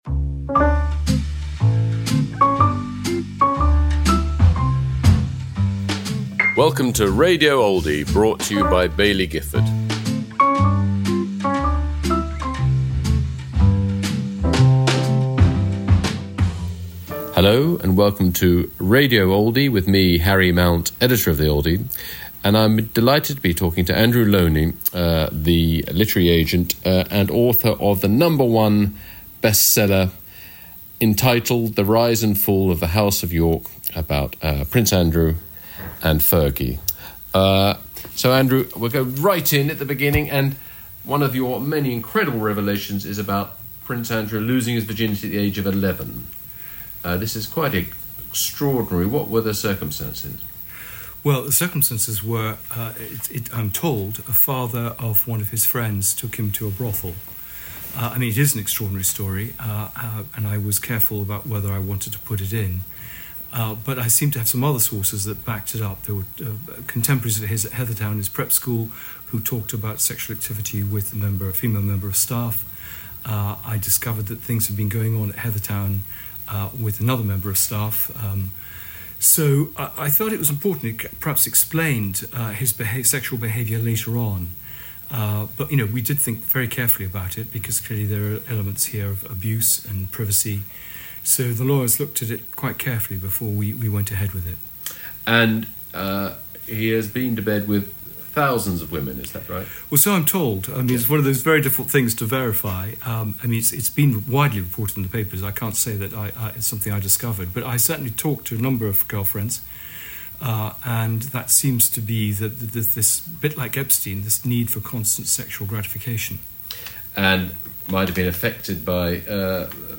Andrew Lownie in conversation with Harry Mount
On Radio Oldie, Prince Andrew's biographer, Andrew Lownie, talks to Harry Mount.